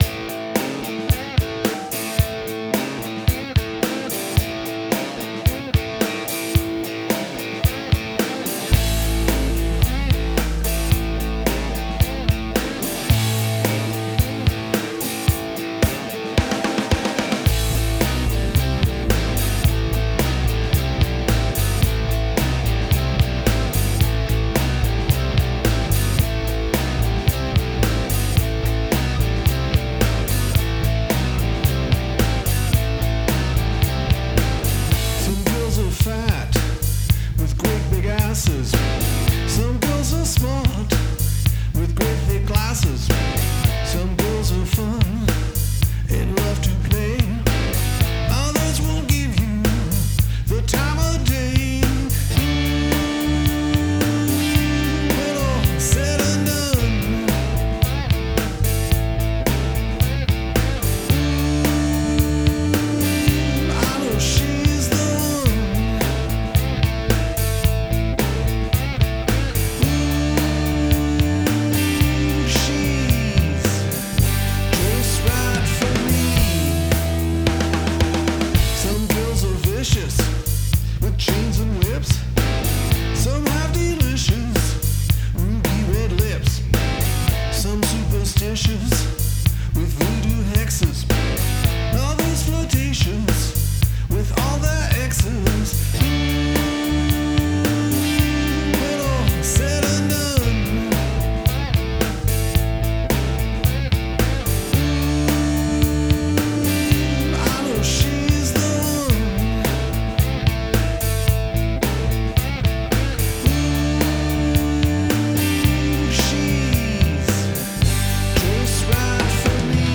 Let me assure all this is not “locker room talk”, but it is Rock and Roll.
For practical performance purposes, I reset the song in standard tuning A, and minimized the overdubs so it would sound much more like the live version.